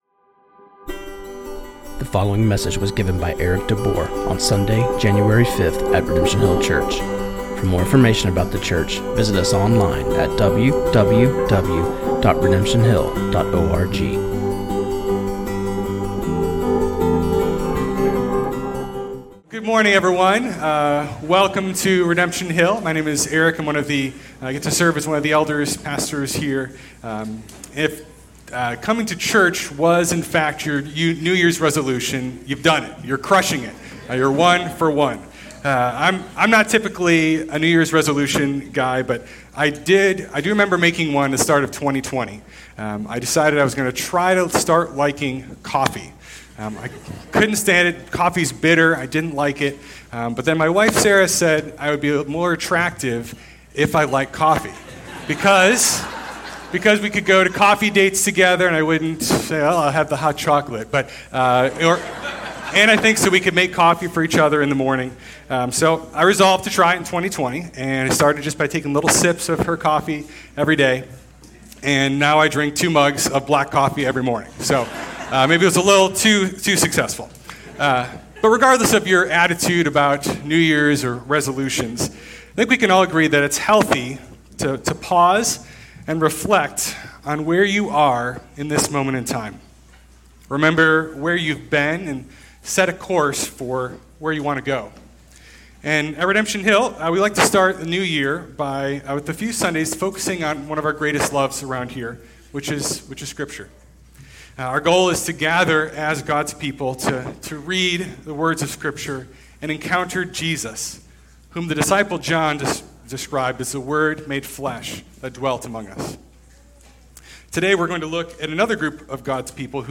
This sermon on Nehemiah 8:1-12 was preached